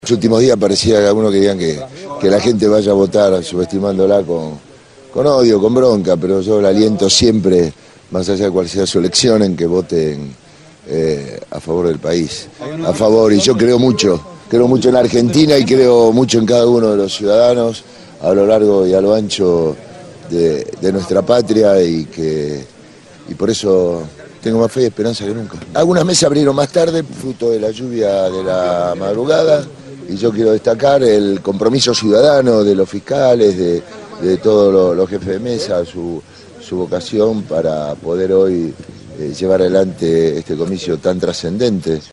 El candidato oficialista Daniel Scioli, líder en las encuestas, culminó la campaña con un acto masivo en el parque Tecnópolis, en la periferia norte de Buenos Aires, apoyado por la militancia kirchnerista.
Palabras de Daniel Scioli